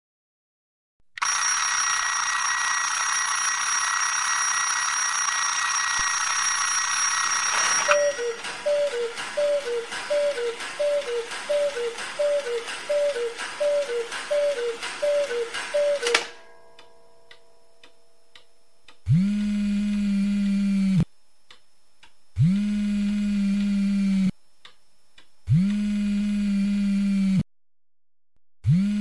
old-clock-phone-mix_24898.mp3